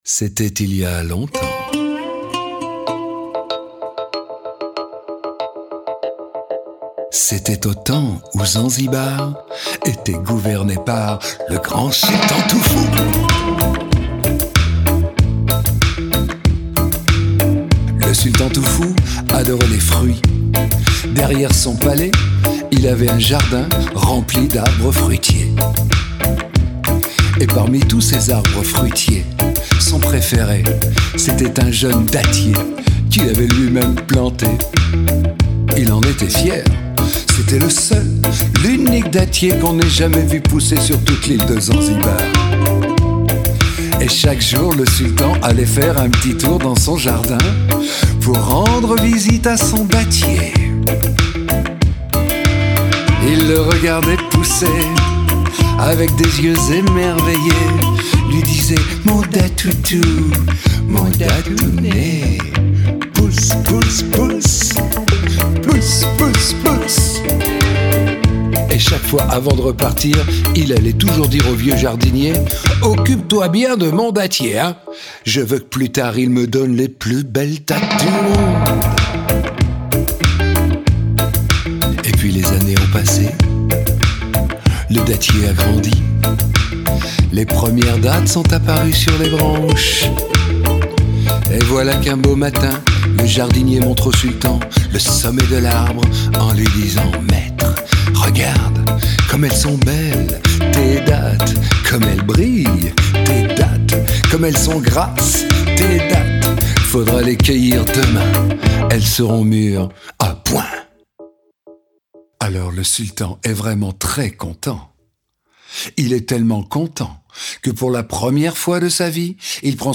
Diffusion distribution ebook et livre audio - Catalogue livres numériques